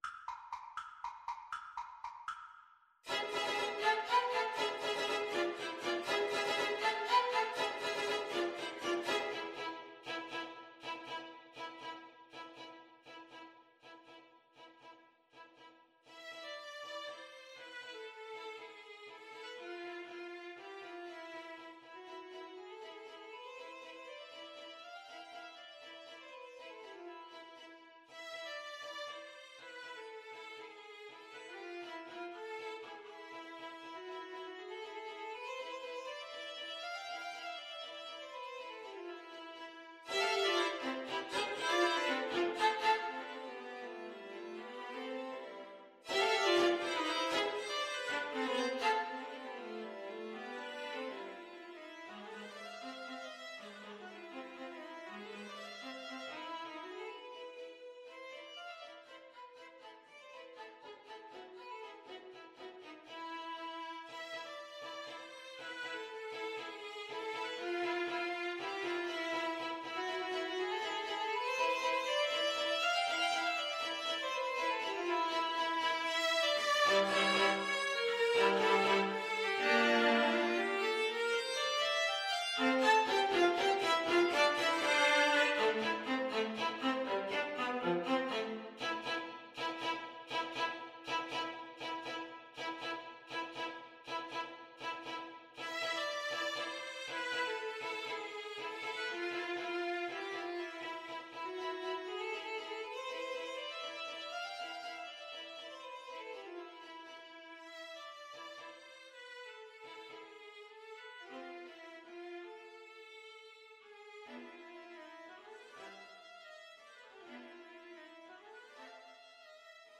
Allegro vivo (.=80) (View more music marked Allegro)
Viola Trio  (View more Advanced Viola Trio Music)